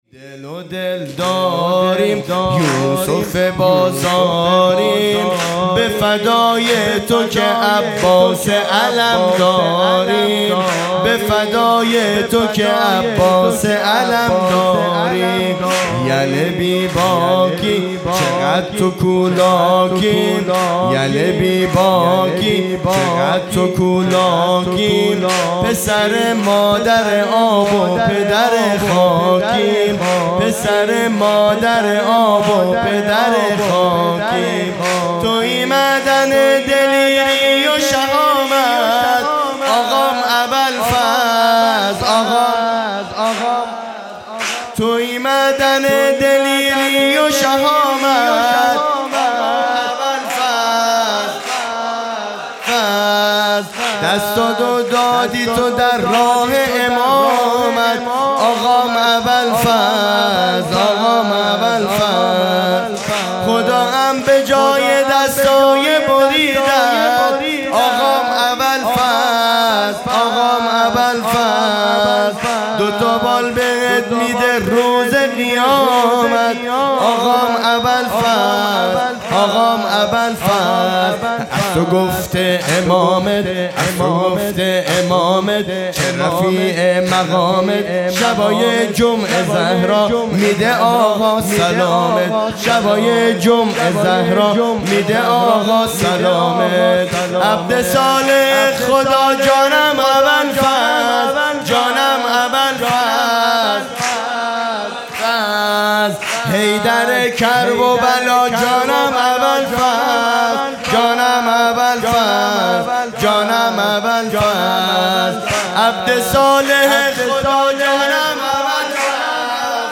0 0 سرود